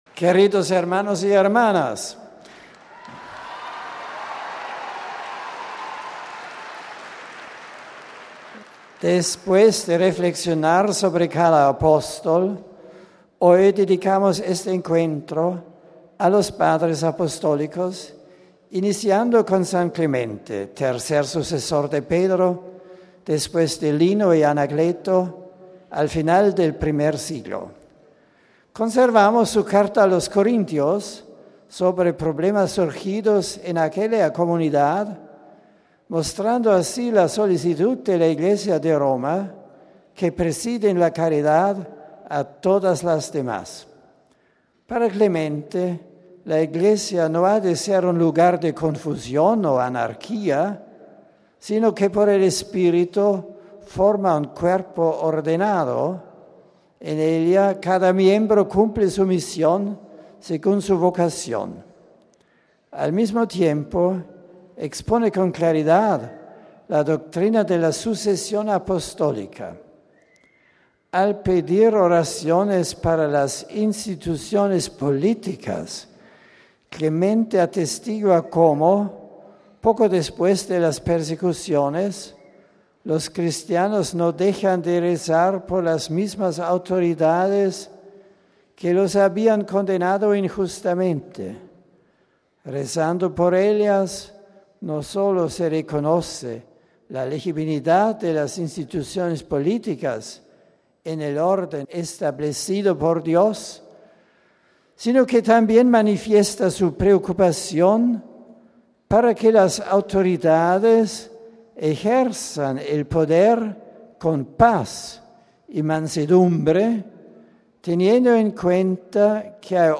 Este ha sido el resumen que de su catequesis ha hecho el Santo Padre en español para los peregrinos de nuestra lengua presentes en el Aula Pablo VI: RealAudio